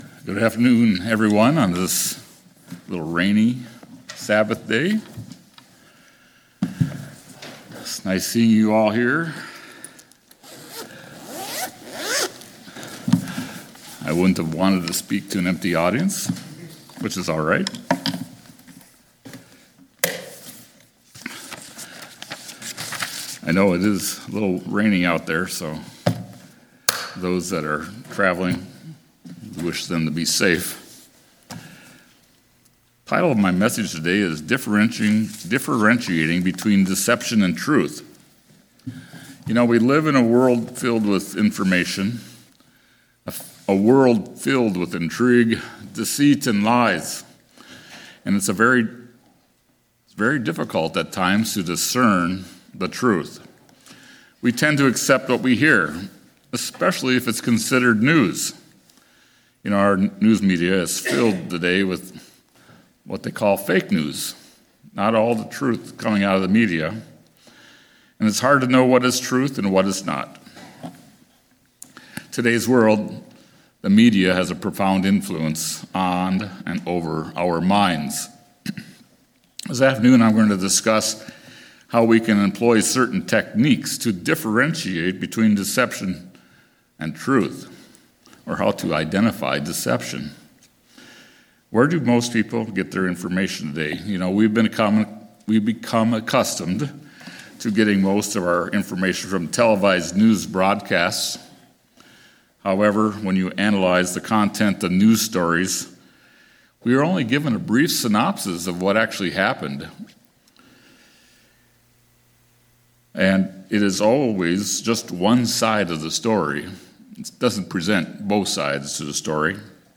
Sermons
Given in Twin Cities, MN